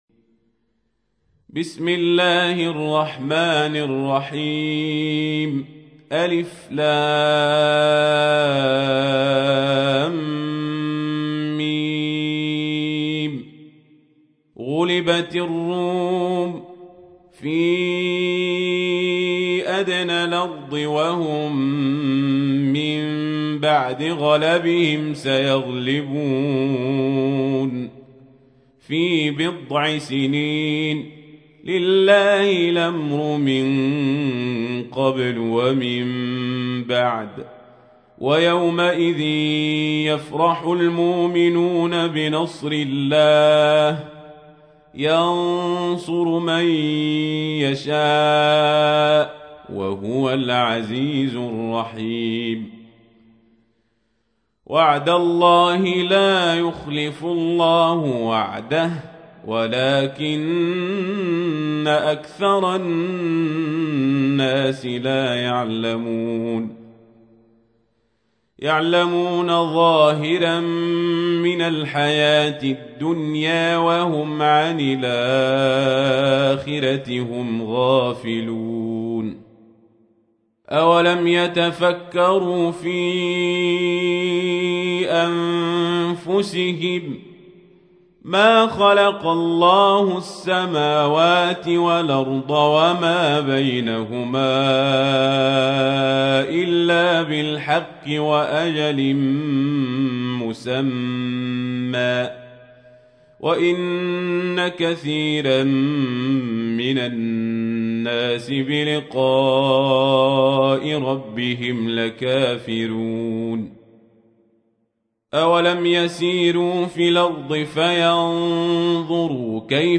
تحميل : 30. سورة الروم / القارئ القزابري / القرآن الكريم / موقع يا حسين